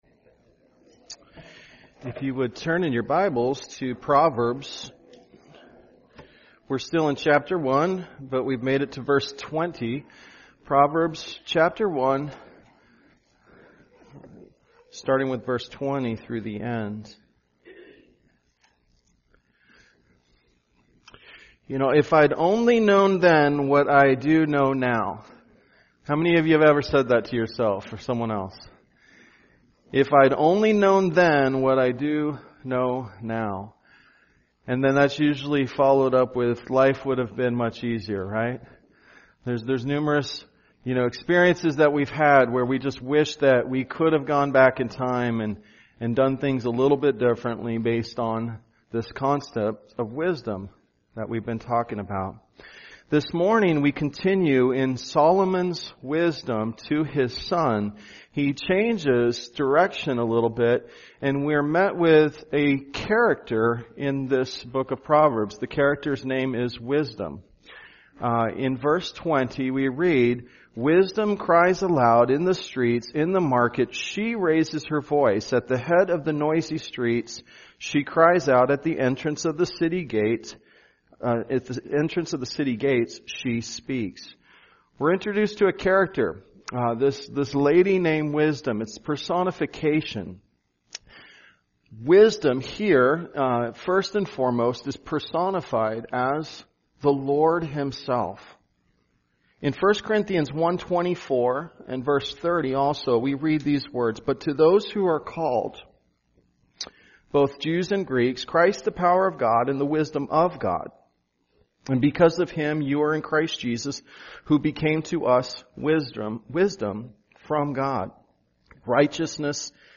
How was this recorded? Sunday Worship